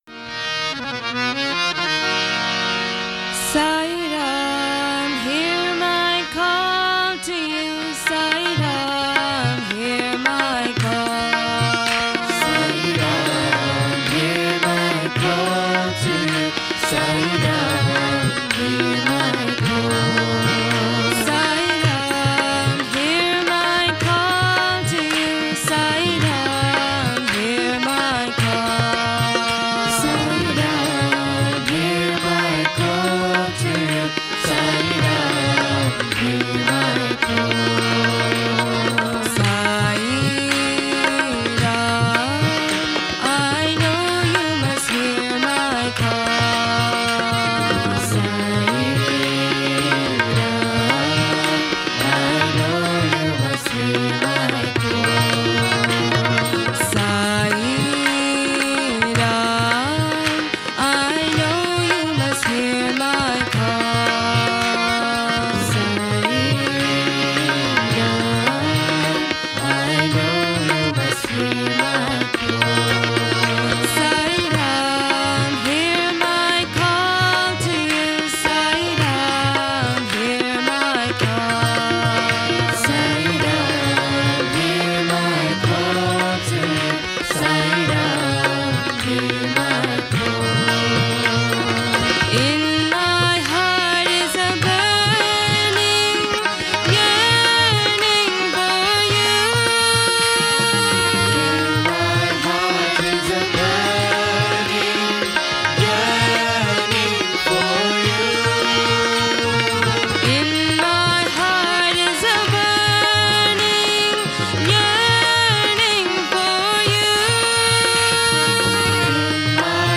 1. Devotional Songs
Minor (Natabhairavi)
8 Beat / Keherwa / Adi